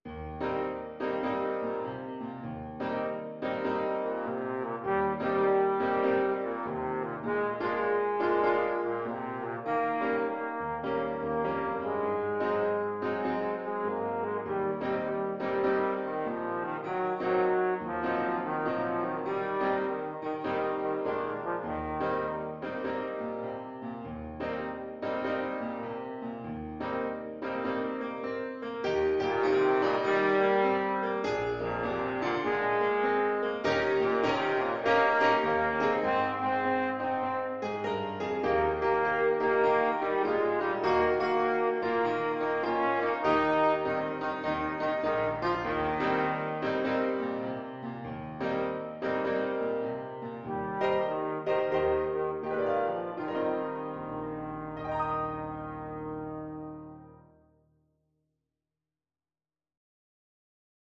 World Austrilasia New Zealand Tohora nui
Trombone
Moderate swing
Eb major (Sounding Pitch) (View more Eb major Music for Trombone )
tohora_nui_TBNE.mp3